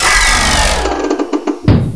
sounds: new technospider sounds